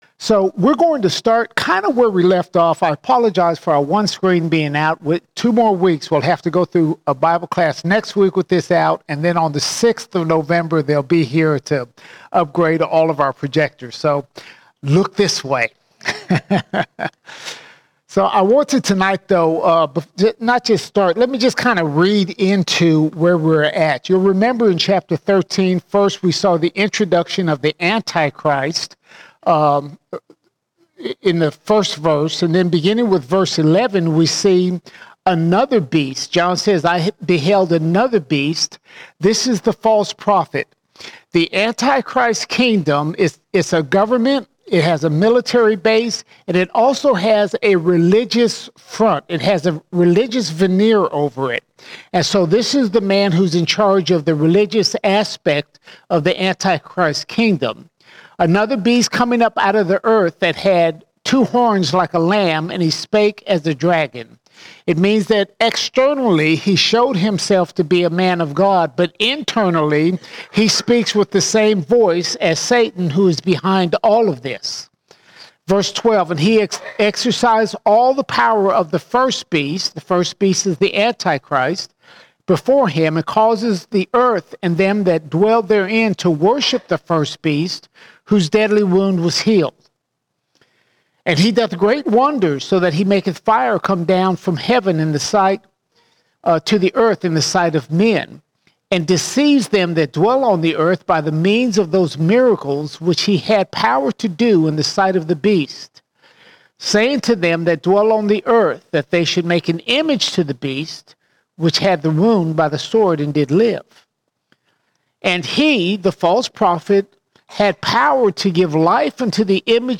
24 October 2024 Series: Revelation All Sermons Revelation 13:15 to 14:20 Revelation 13:15 to 14:20 We examine the Mark of the Beast and the harvest of God's wrath.